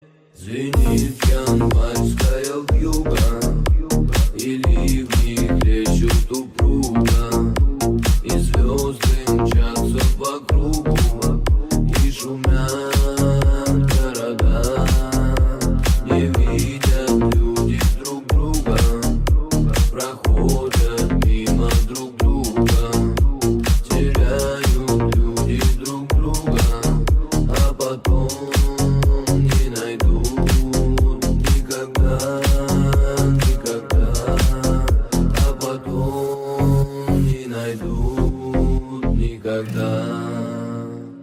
• Качество: 320, Stereo
мужской вокал
remix
Electronic
EDM
клубняк
house
Клубная версия известной песни